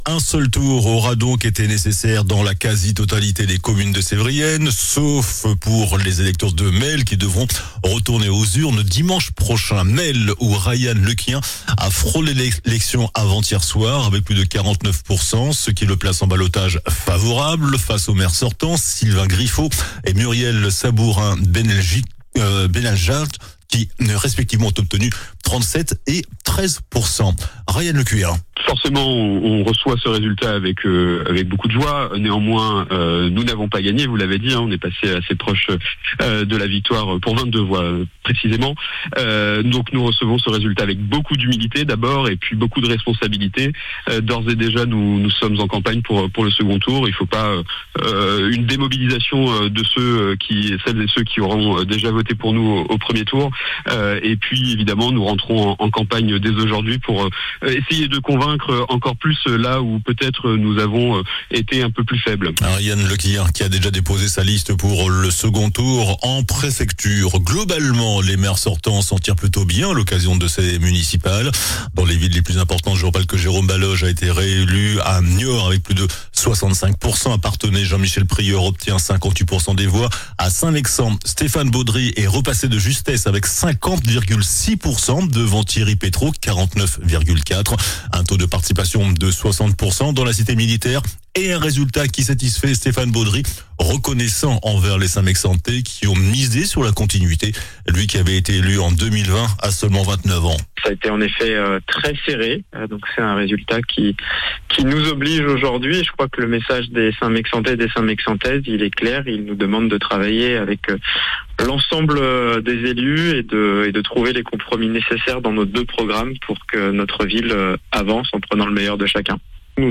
JOURNAL DU MARDI 17 MARS ( MIDI )